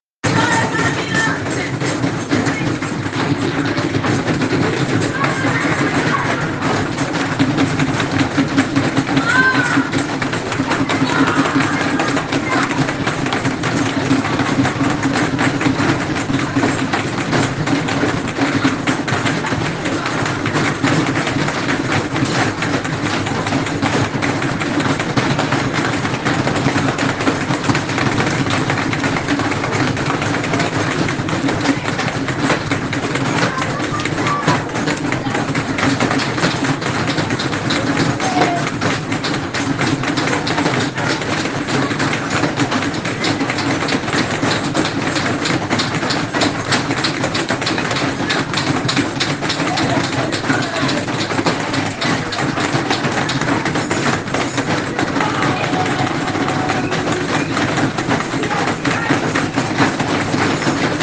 Gli alunni/e di tutte le classi, hanno fatto in modo che il loro silenzio si facesse sentire, battendo le mani sui banchi creando un rumore quasi surreale all’interno dell’istituto.